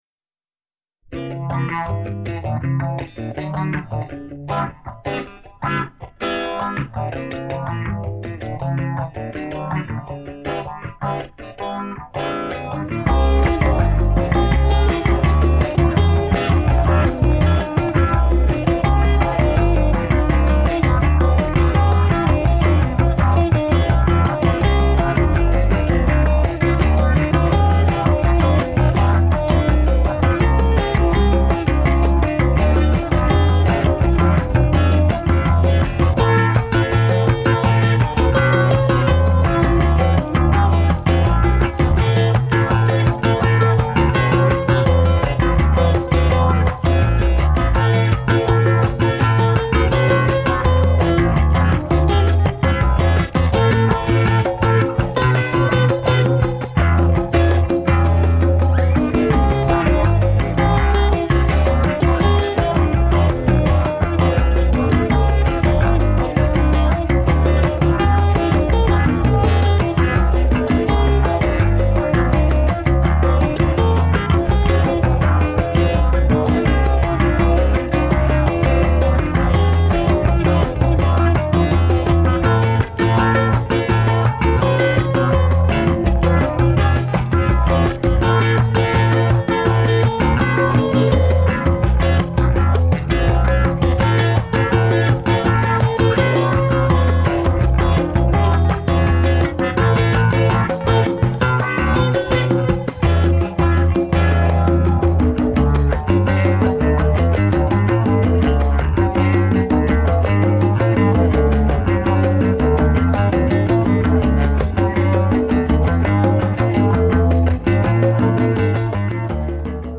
Funk Indie